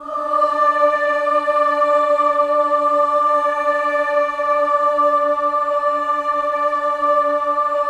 VOWEL MV10-L.wav